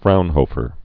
(frounhōfər)